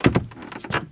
MISC Wood, Foot Scrape 03.wav